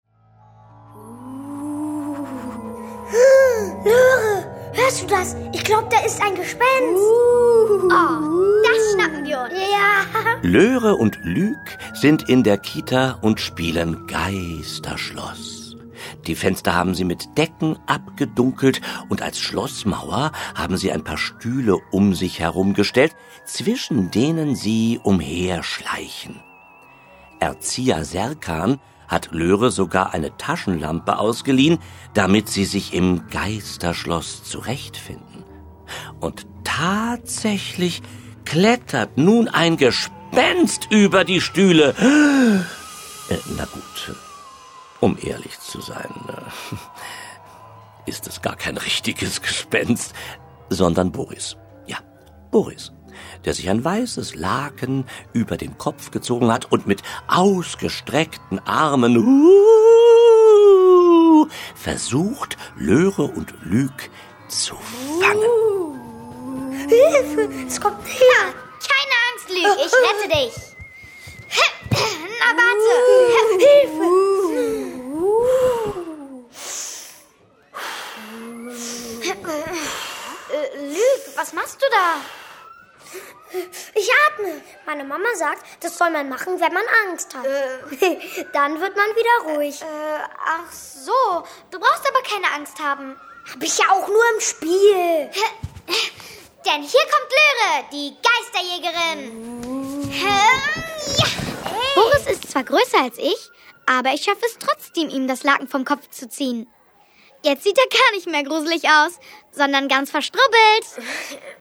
Hörspiele mit Ilja Richter u.v.a. (1 CD)
Themenwelt Kinder- / Jugendbuch Erstlesealter / Vorschulalter